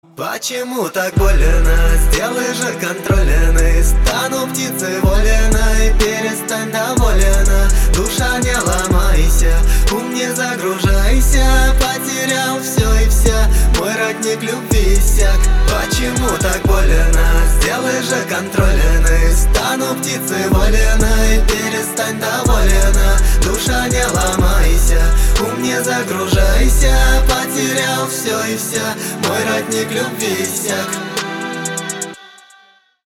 • Качество: 320, Stereo
Хип-хоп
грустные
русский рэп
спокойные